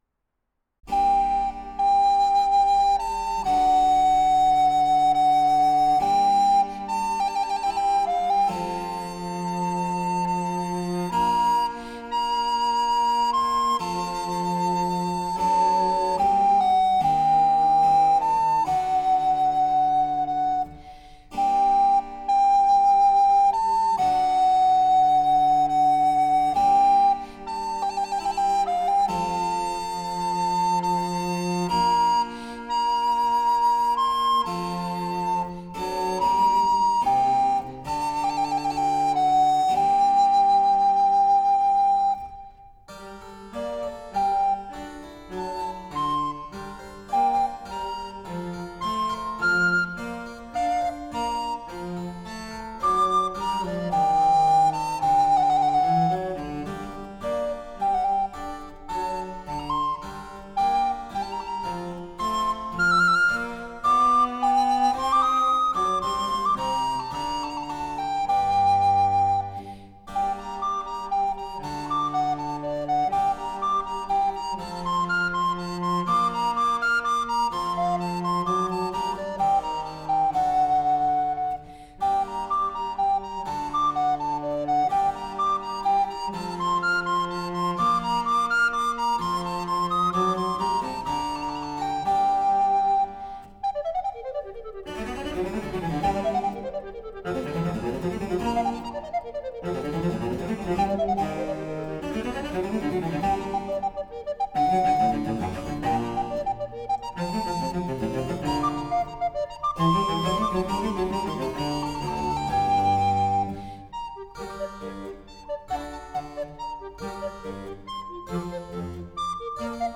Recorders
harpsichord/piano
violoncello
장르: 시즈널, 바로크